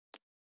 446リップクリーム,口紅,ふたを開ける,
効果音